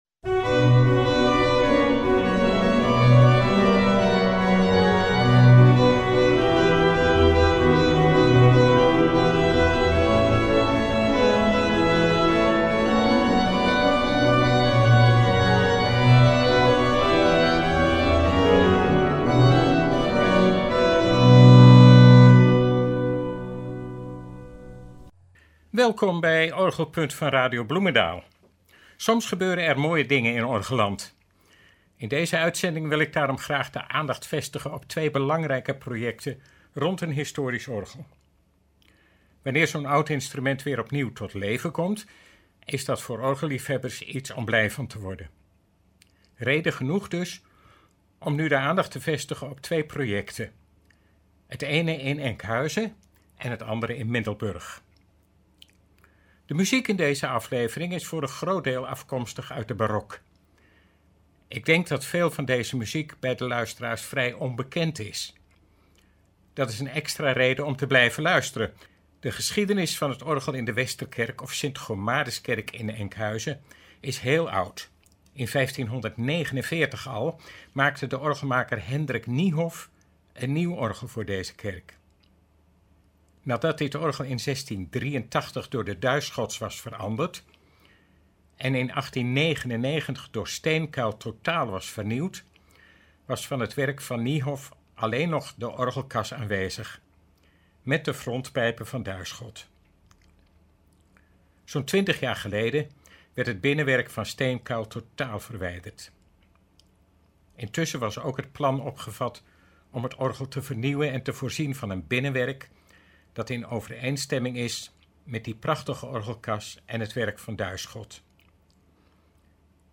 Eind 2021 kon de eerste fase van dit project worden opgeleverd: het complete Bovenwerk. In de uitzending is het resultaat daarvan te beluisteren.